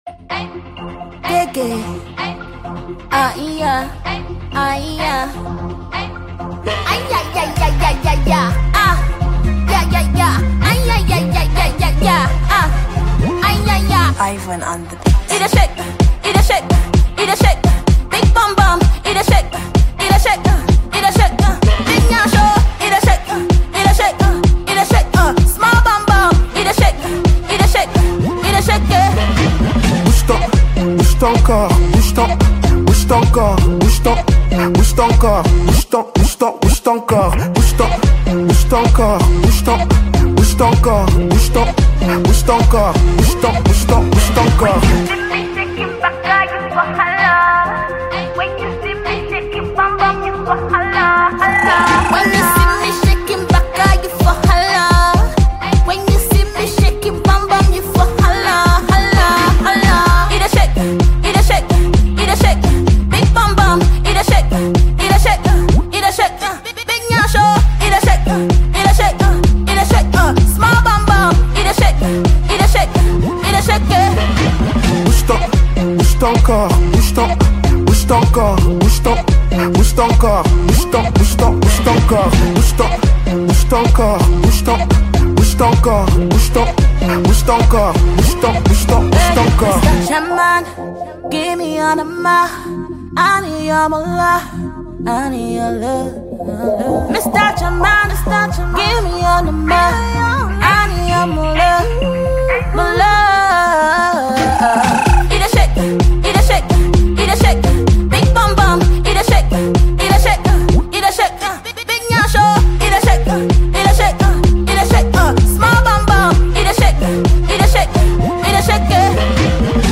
Ghana Music Music
catchy tune